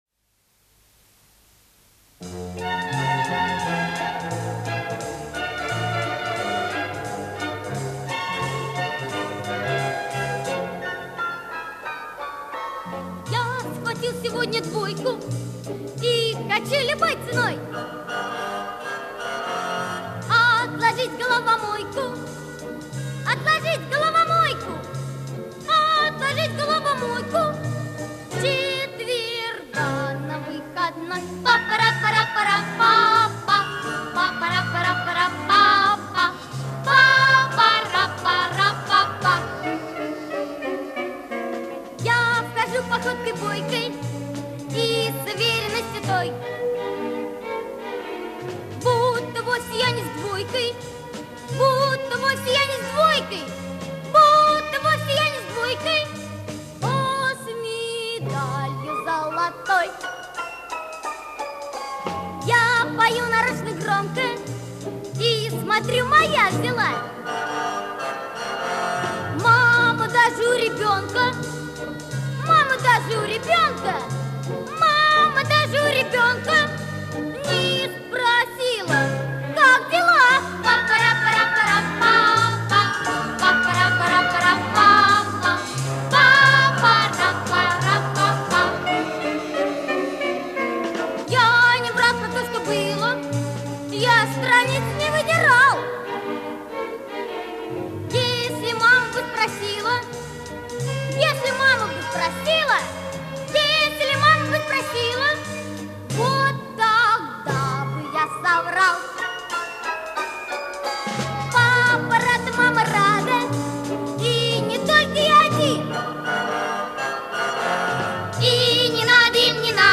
в исполнении детского хора